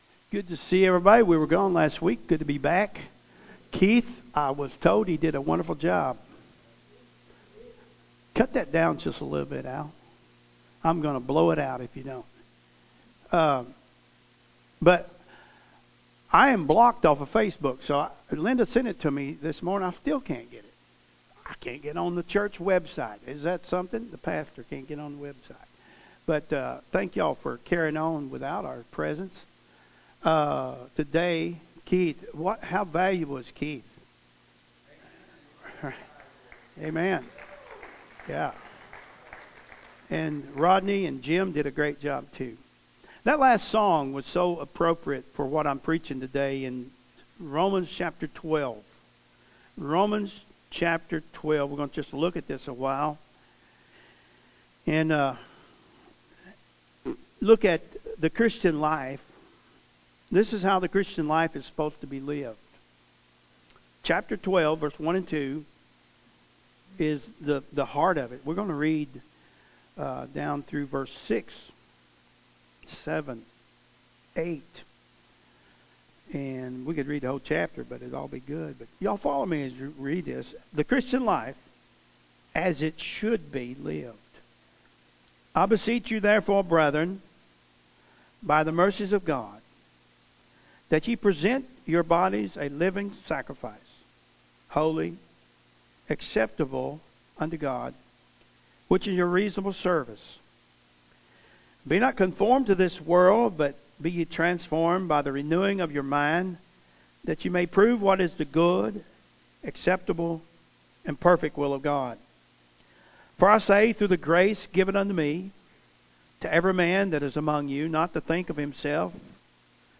Sermons Sunday Morning Service